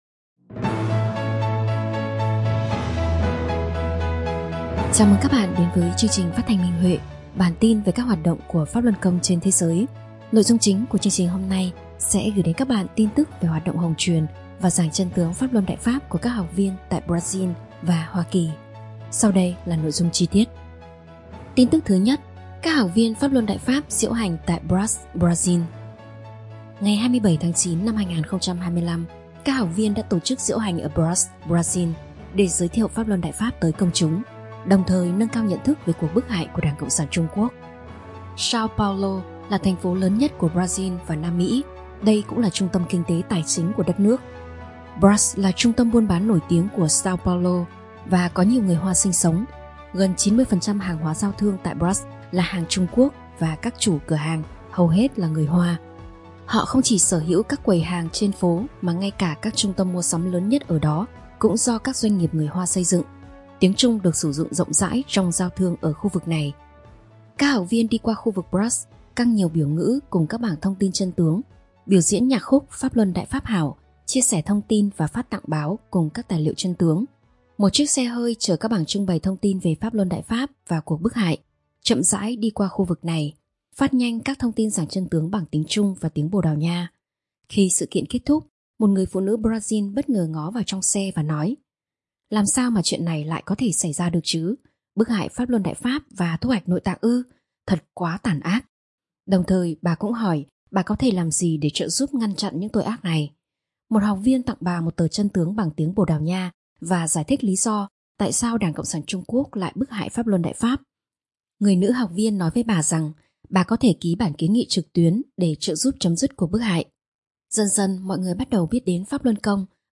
Chương trình phát thanh số 387: Tin tức Pháp Luân Đại Pháp trên thế giới – Ngày 10/10/2025